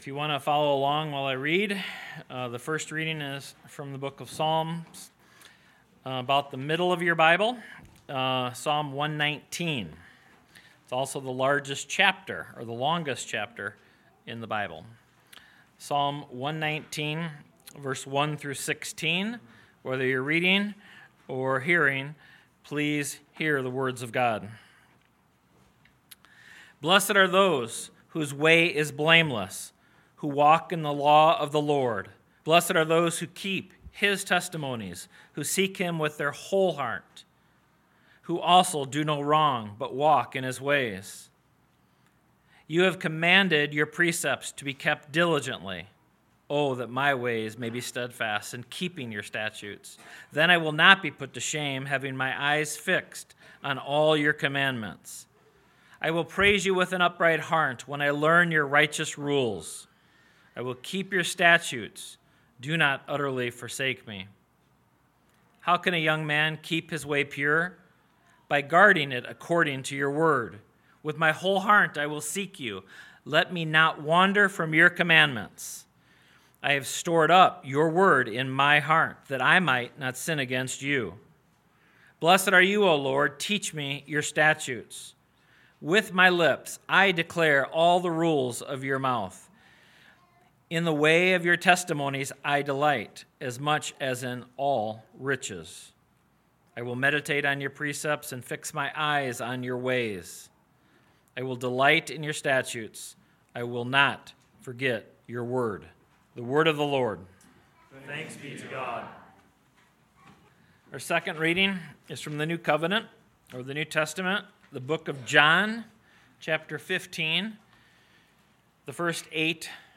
Sermon Text: Hebrews 5:11-6:8 First Reading: Psalm 119:1-16 Second Reading: John 15:1-8